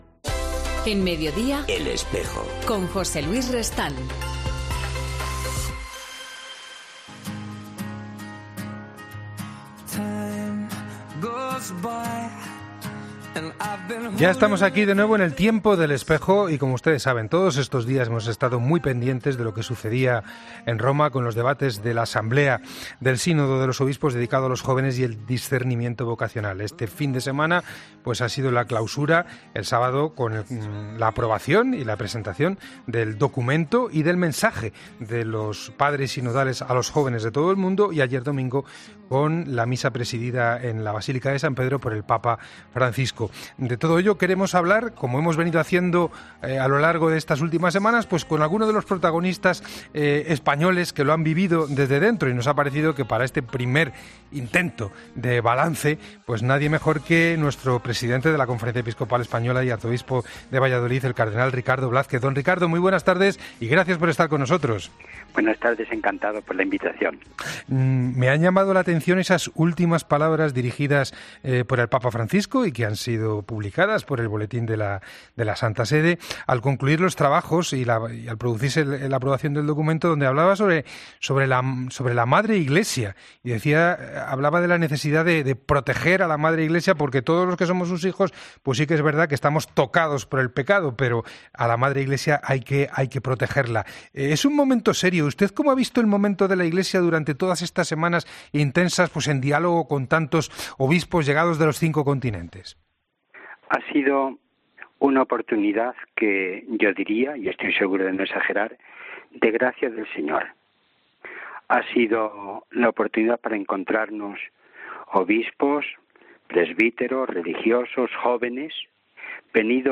Recién llegado de Roma, el cardenal Ricardo Blázquez ha hecho en 'El Espejo' balance del Sínodo de Obispos sobre los jóvenes